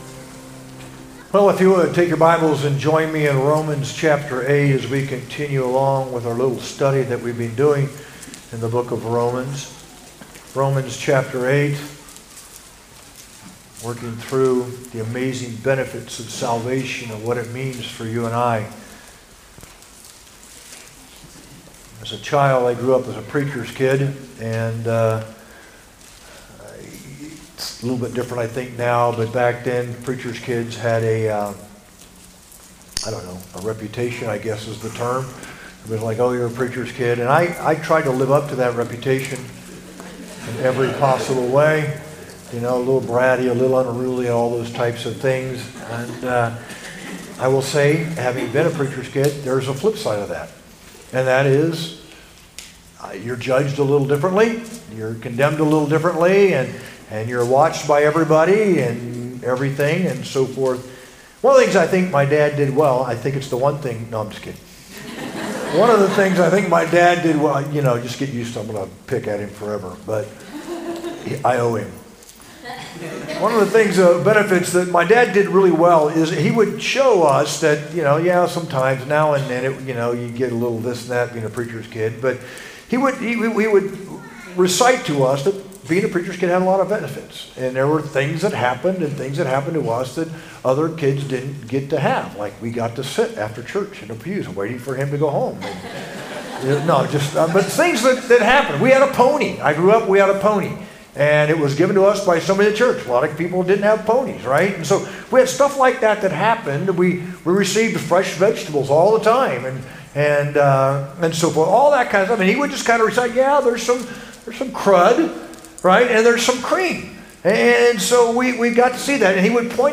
sermon-11-3-24.mp3